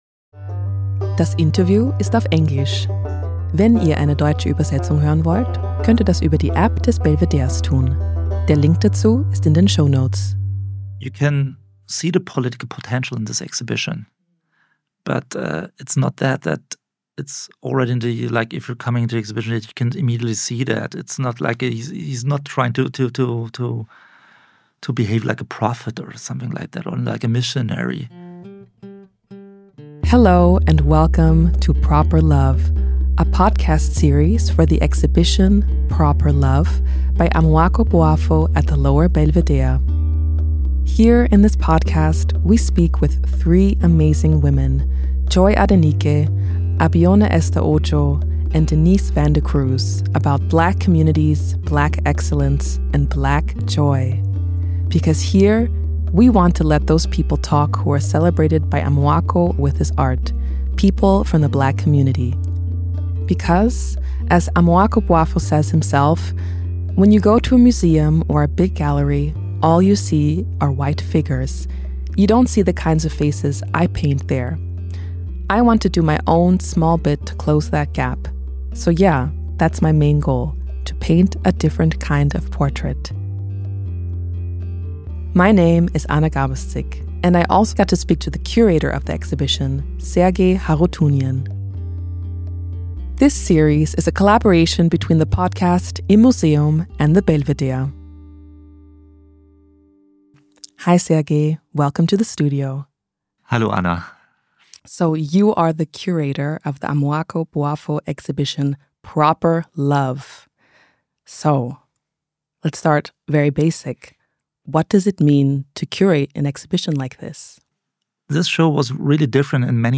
Dieses Gespräch ist auf Englisch.